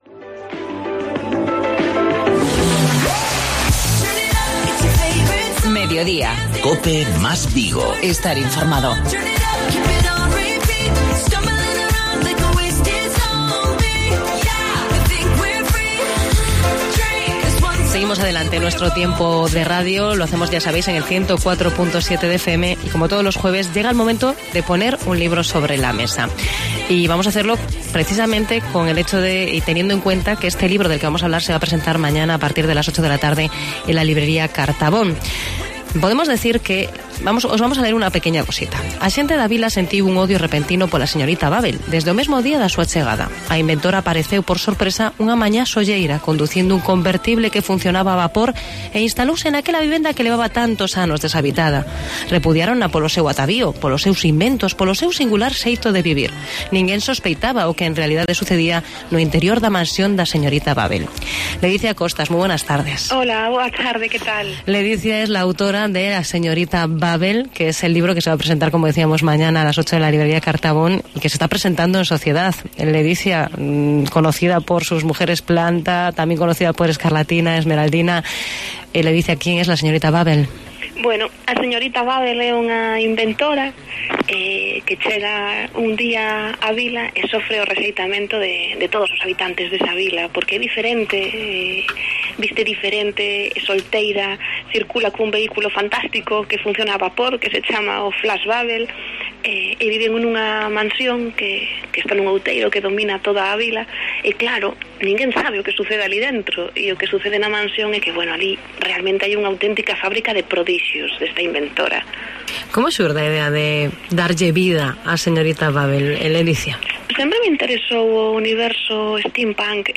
Una inventora inglesa, un mansión abandonada, una fábrica de prodigios, una protagonista con seis dedos... todo ello forma parte "A Señorita Bubble", la nueva novela de Ledicia Costas. Hoy en nuestra sección "Ponemos un libro sobre la mesa" hemos charlado con la autora de este nuevo personaje y de su historia.